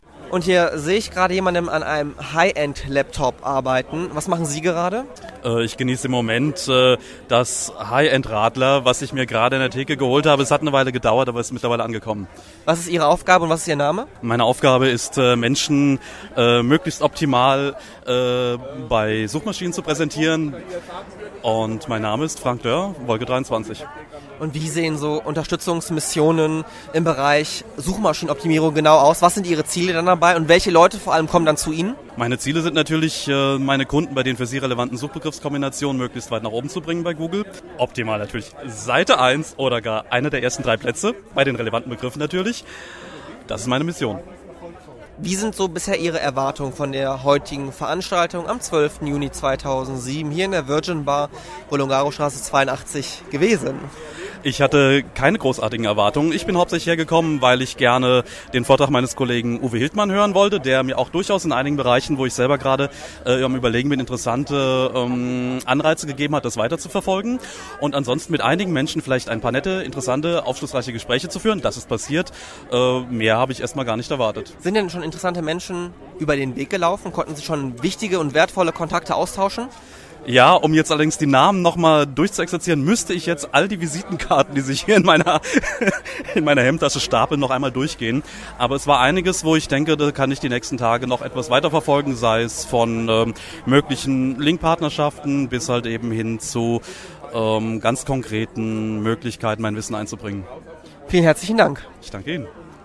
Unglaublich, wenn man feststellt, wie schnell man so redet.
RheinMainNetwork Interview